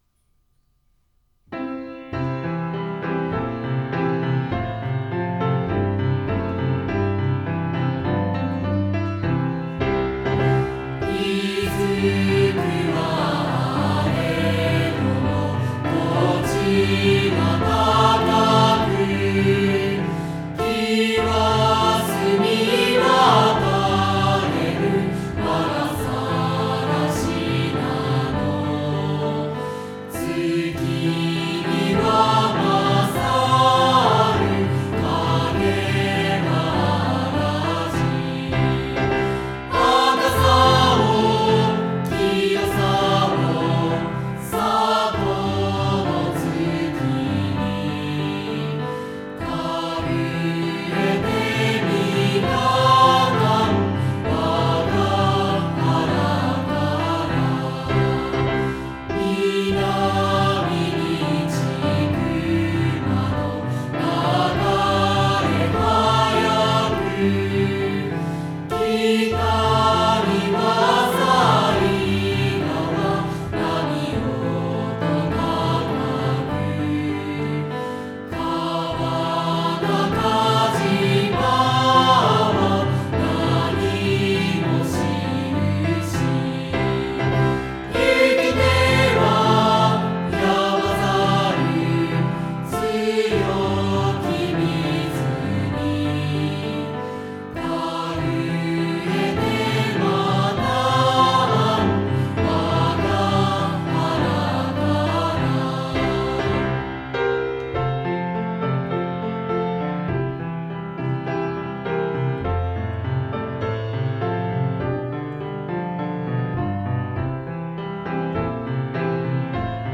篠ノ井高校校歌（歌入り）